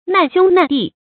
注音：ㄣㄢˋ ㄒㄩㄥ ㄣㄢˋ ㄉㄧˋ
難兄難弟的讀法